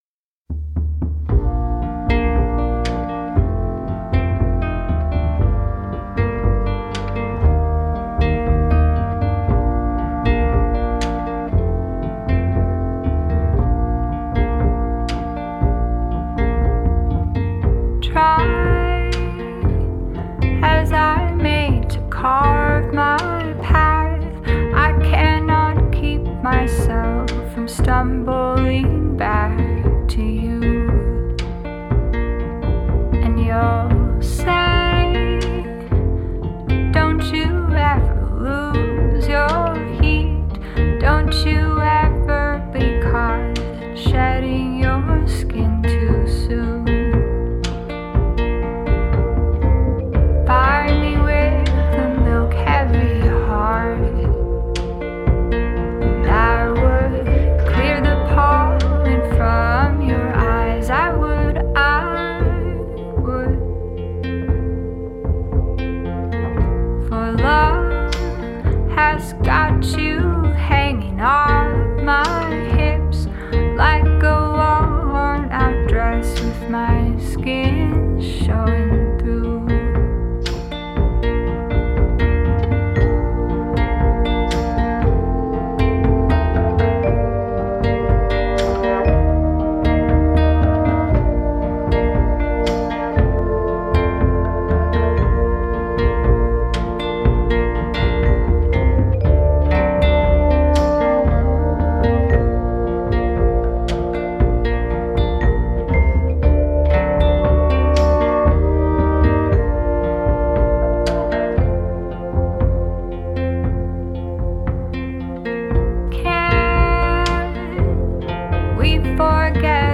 ヴィンテージかつスモーキーな歌声とレイドバックしつつも瑞々しさを保った絶妙な音の質感がとにかく素晴らしいですね！